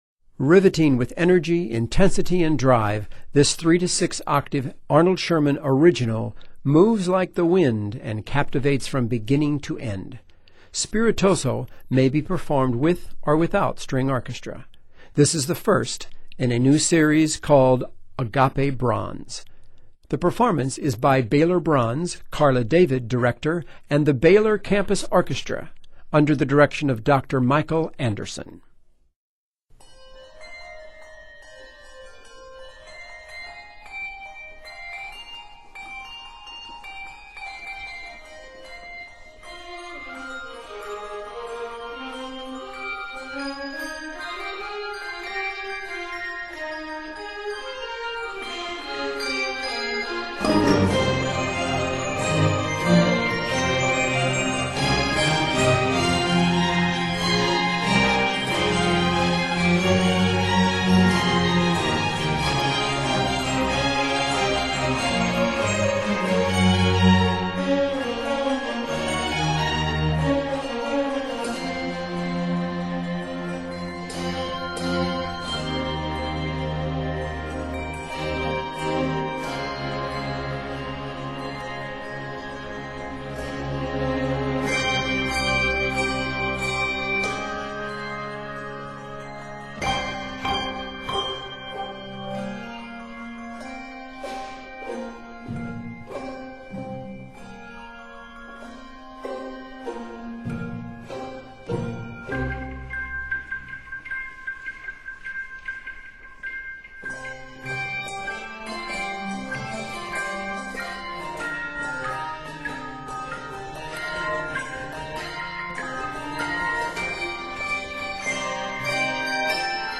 Riveting with energy, intensity and drive
It is written in c minor and is 180 measures.